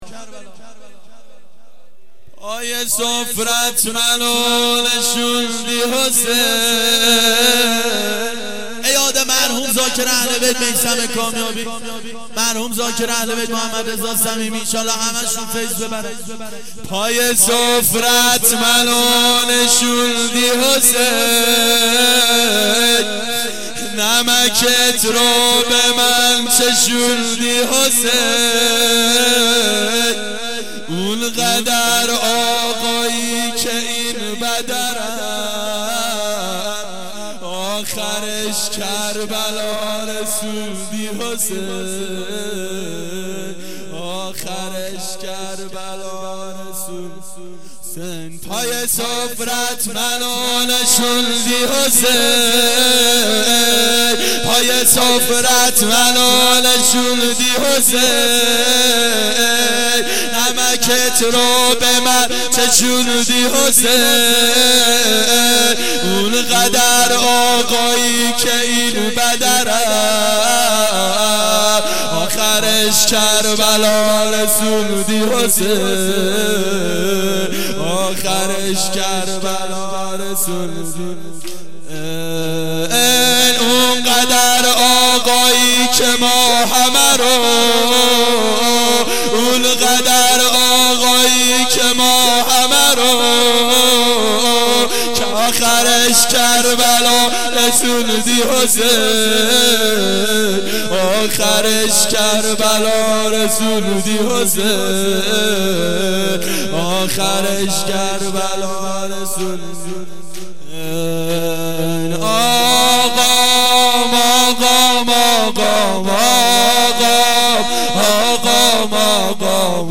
گلچین جلسات هفتگی سال 1387